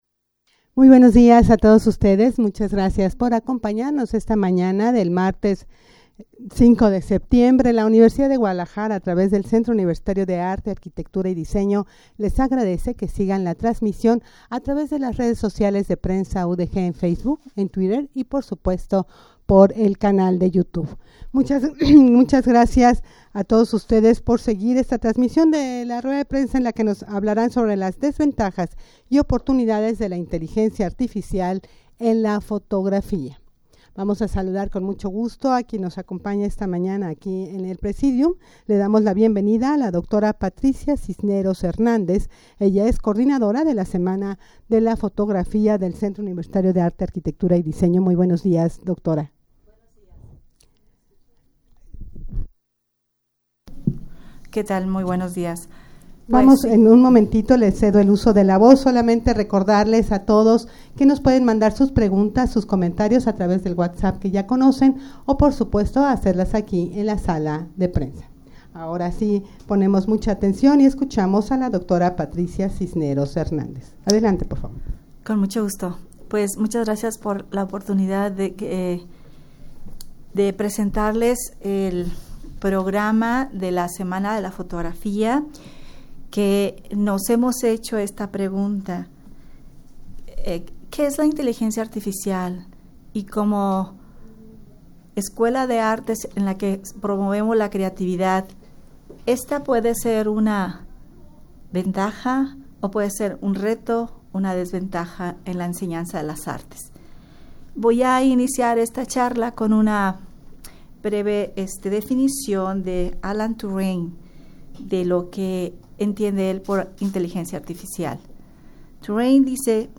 Audio de la Rueda de Prensa
-rueda-de-prensa-desventajas-y-oportunidades-de-la-inteligencia-artificial-en-la-fotografia.mp3